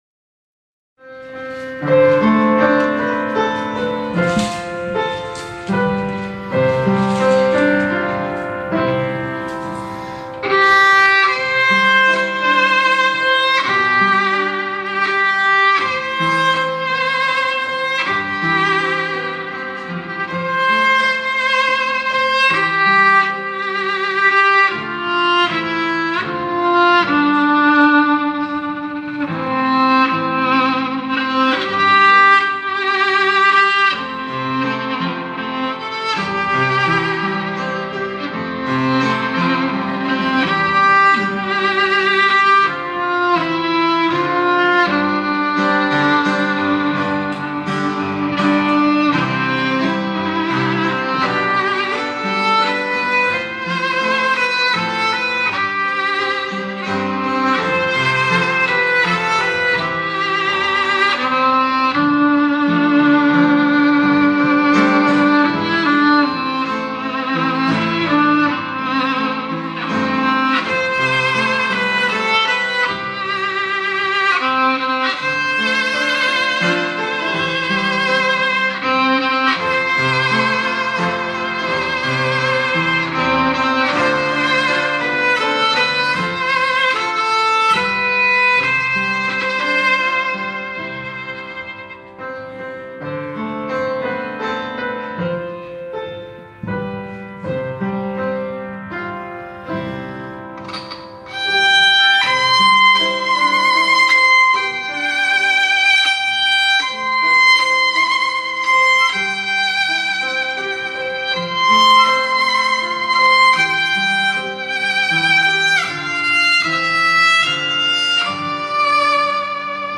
바이올린 연주